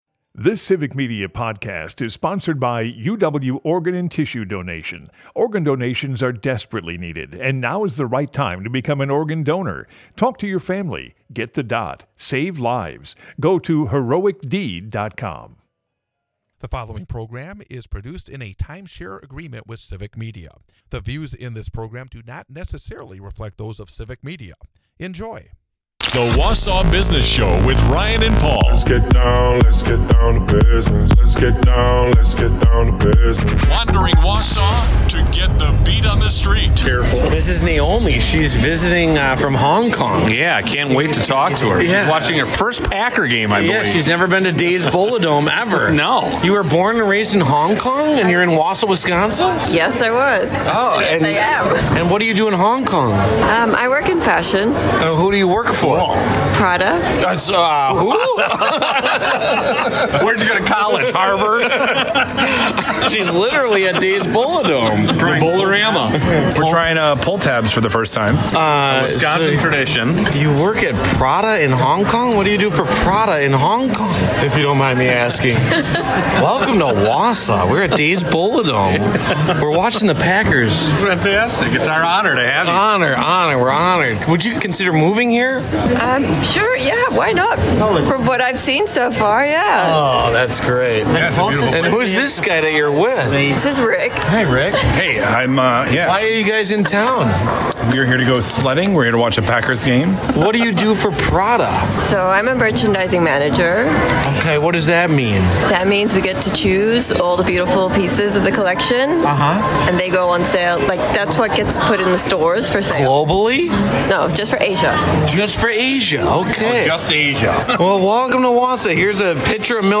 And we have our first contestant for the FRIEND OF A FRIEND Competition for $10,000! Sprinkle in some 2024 Highlights and 2025 New Year’s Resolutions and you’ve got Episode #38 of The Wausau Business Show, this Saturday The Wausau Business Show is a part of the Civic Media radio network and airs Saturday from 8-9 am on WXCO in Wausau, WI.